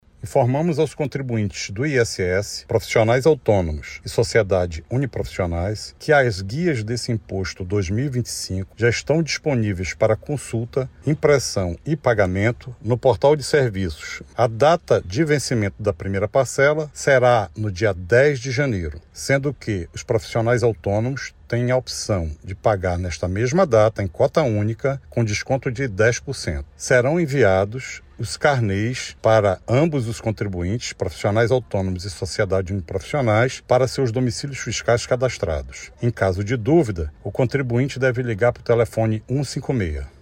O vencimento das guias, tanto para a parcela única quanto para a primeira parcela do pagamento parcelado, será no próximo dia 10/1. Quem optar pela parcela única terá um desconto de 10%, já o parcelamento pode ser realizado em até 12 vezes, sem desconto, como explica o Secretaria Municipal de Finanças e Tecnologia da Informação (Semef), Armando Simões.